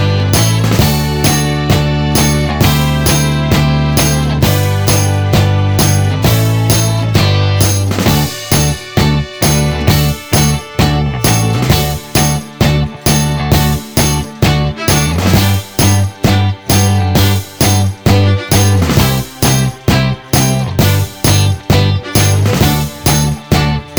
Live Version Pop (1960s) 3:45 Buy £1.50